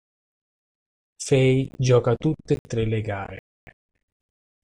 Hyphenated as tùt‧te Pronounced as (IPA) /ˈtut.te/